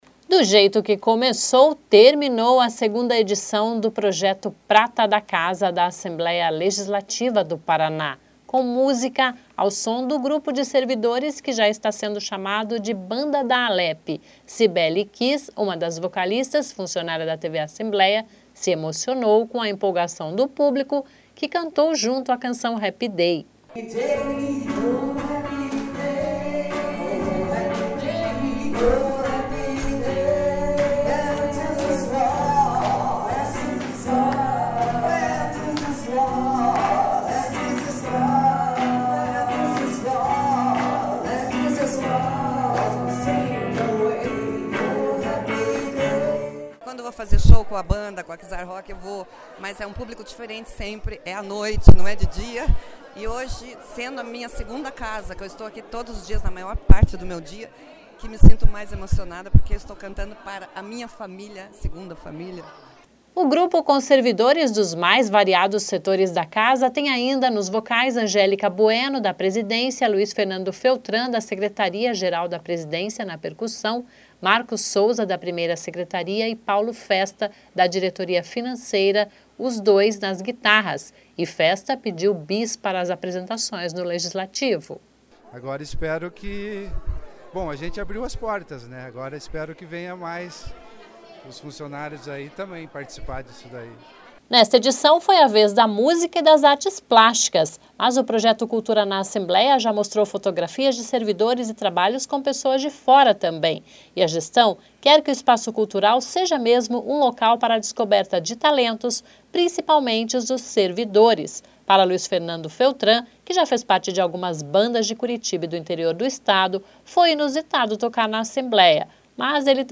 (Sonora)
(Sobe som e sonora)